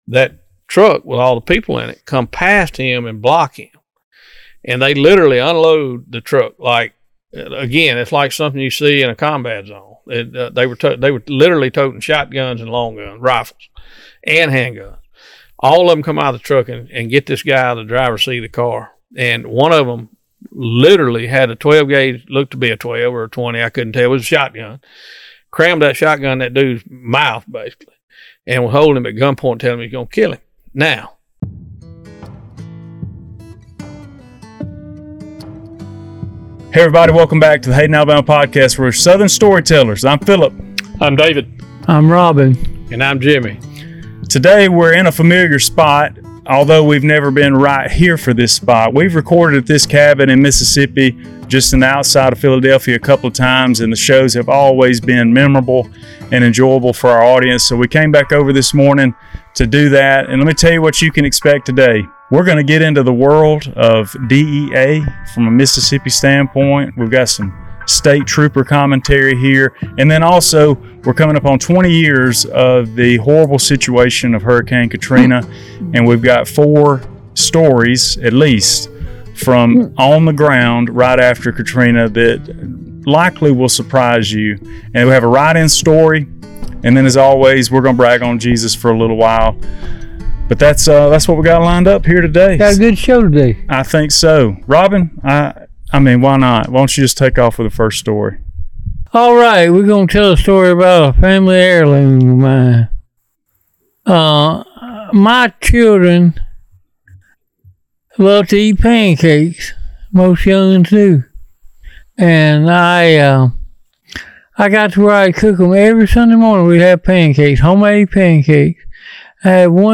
In this storytelling episode of the Hayden Alabama Podcast, we dig into wild drug busts, undercover operations, and the dangers that come with chasing criminals.